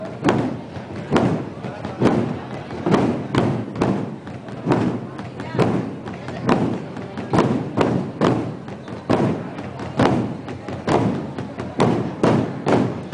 Tambores roncos
Hdad. del Cristo de la Humildad (Cebolletas)